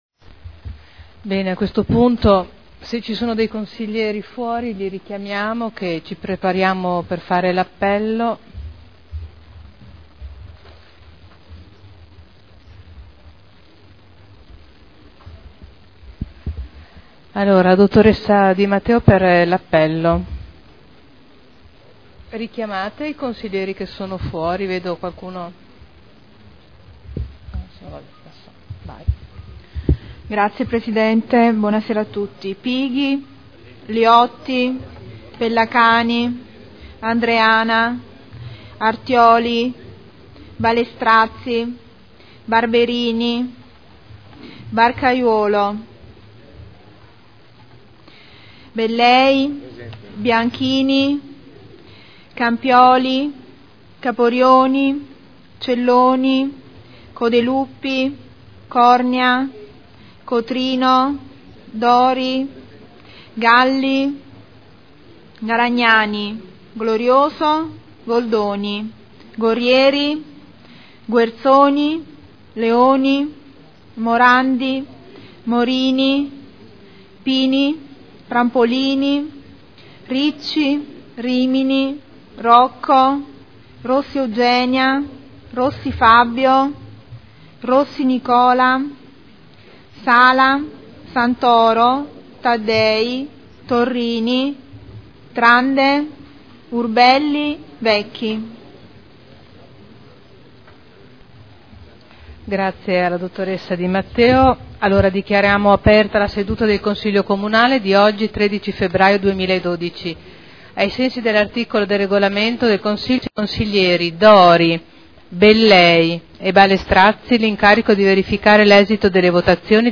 Seduta del 13 febbraio Apertura del Consiglio Comunale. Appello